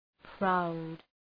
Προφορά
{praʋd}